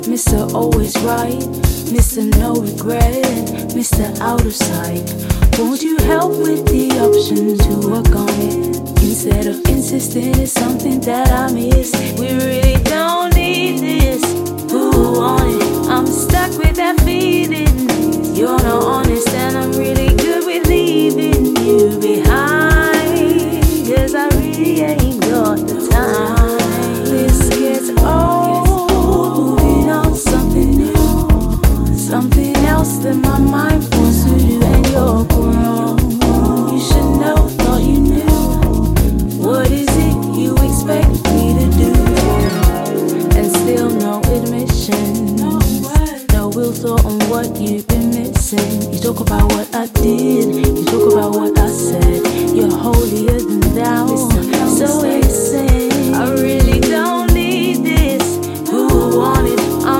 Modern London soul
Broken beat